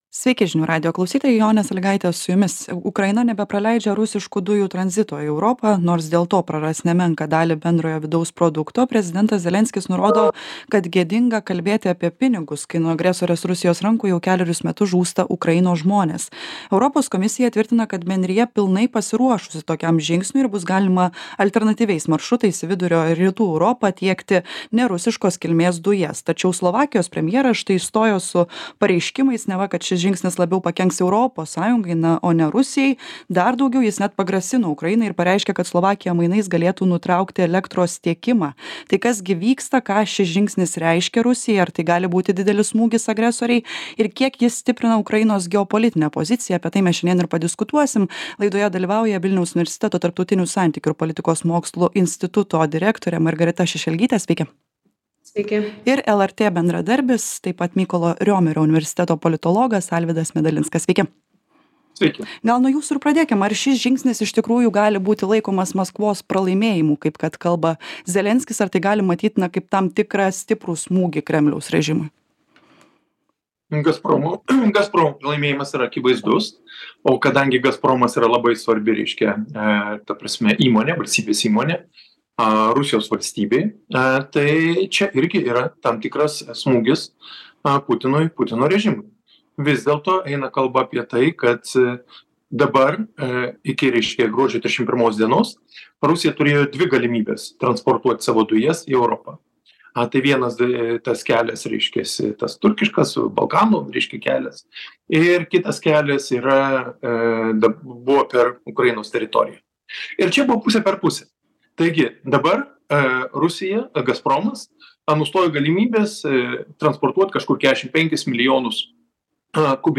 Aktualusis interviu Klausykitės Atsisiųsti Atsisiųsti Ukraina nutraukia rusiškų dujų tranzitą per šalį.